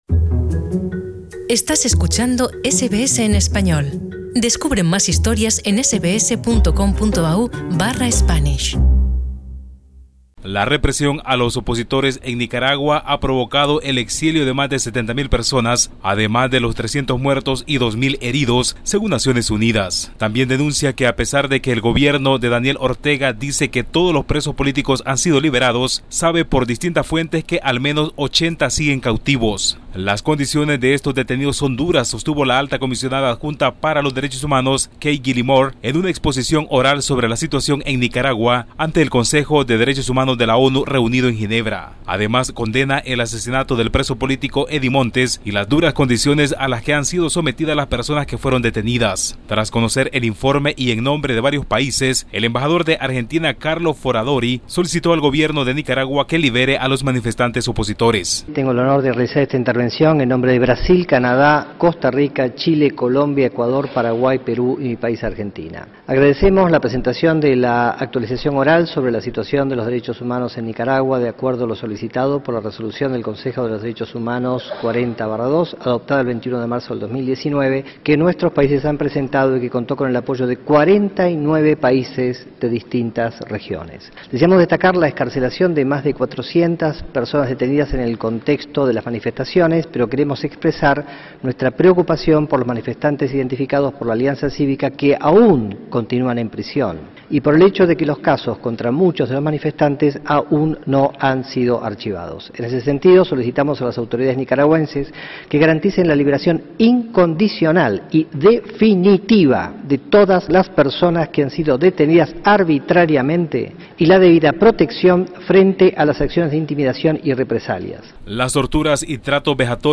Escucha el podcast con el informe completo de nuestro corresponsal en Centroamérica.